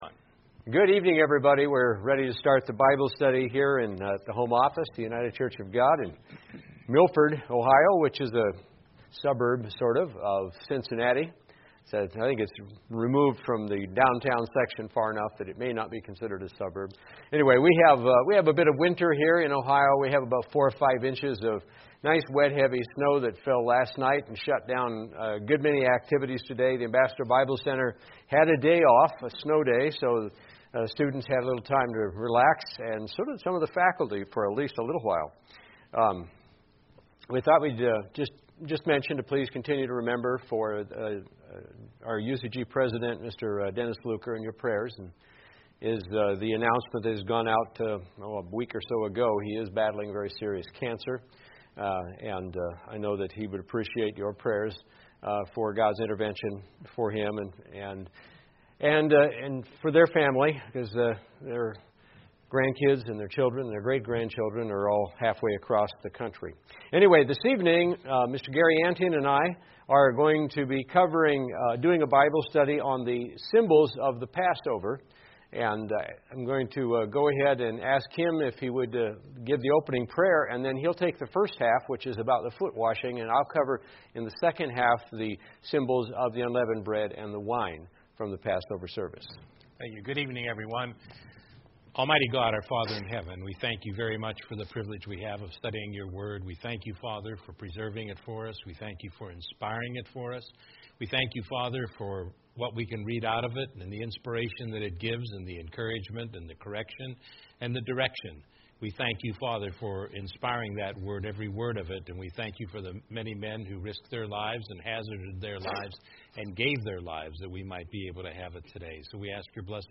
Bible Study: Passover Symbolism and Meaning | United Church of God
beyond-today-bible-study-passover-symbolism-and-meaning_0.mp3